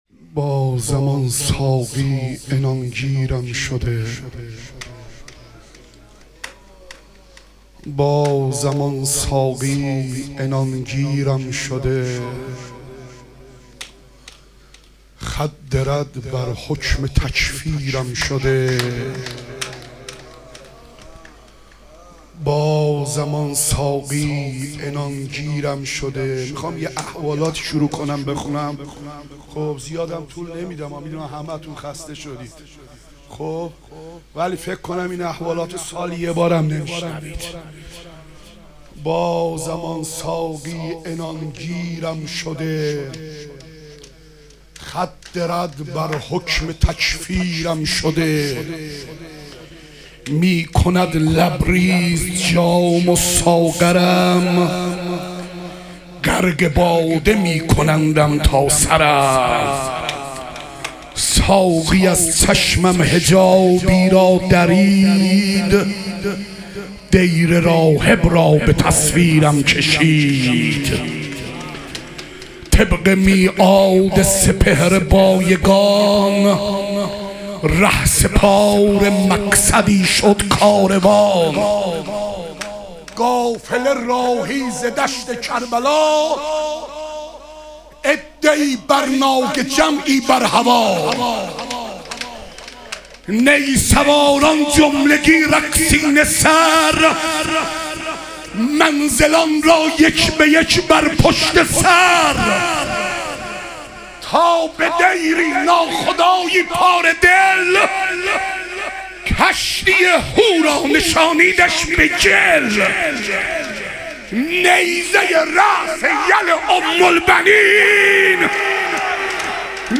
مدح و رجز
شب اربعین امیرالمومنین علیه السلام